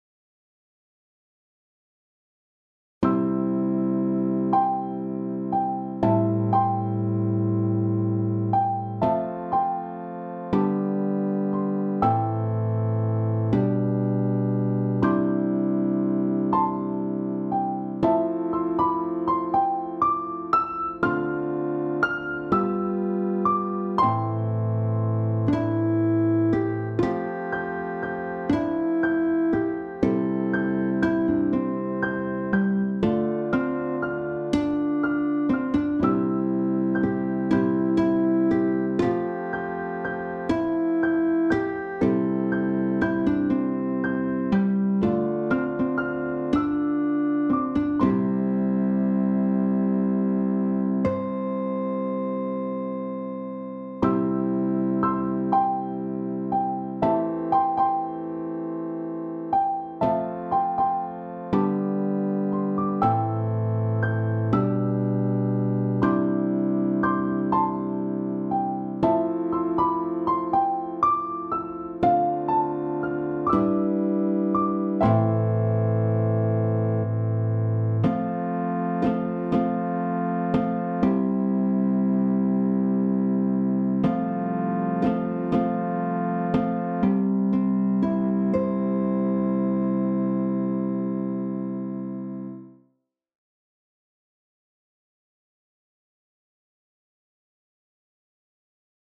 BGM
ショートスローテンポ明るい穏やか